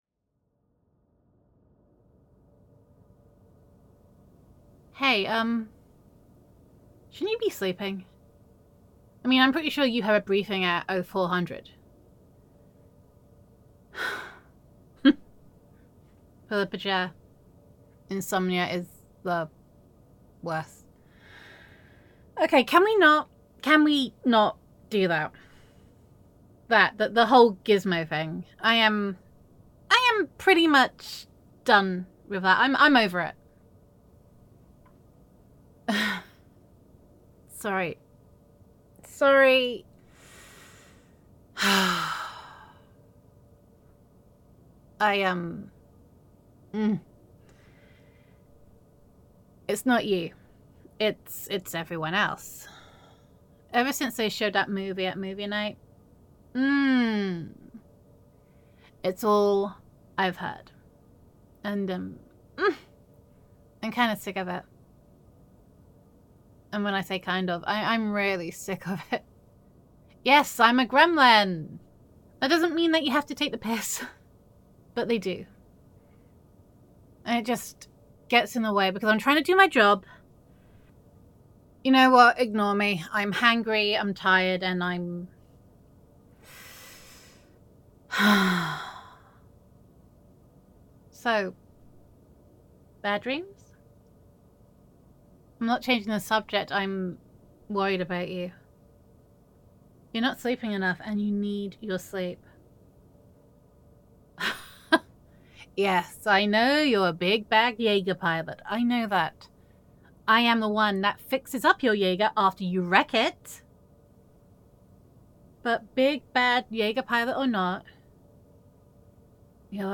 [F4A]
[Gremlin Roleplay]